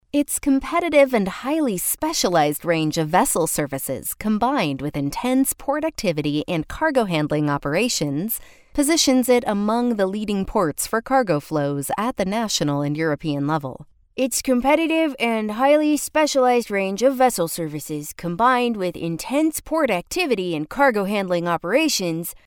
Voiceovers American English  female voice overs. Group A